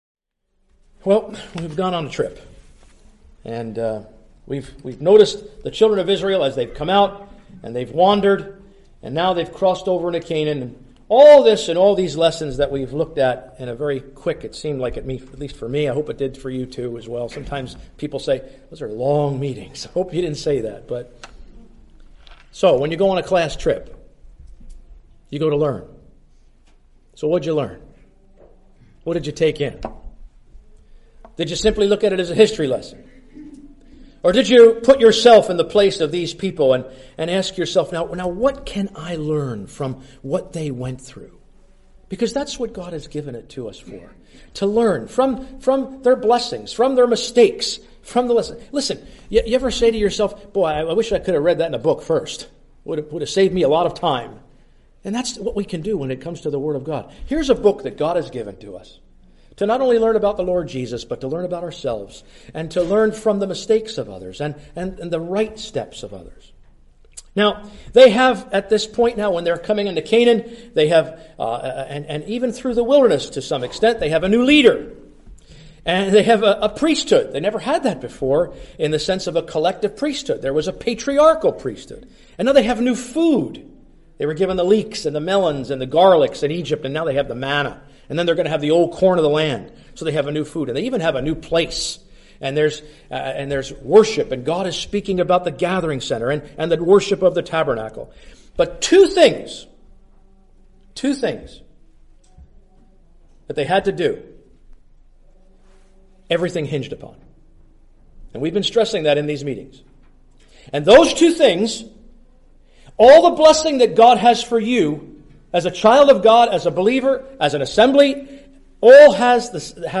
(Recorded in Marion Gospel Hall, Iowa, USA)